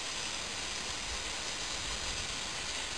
steam.wav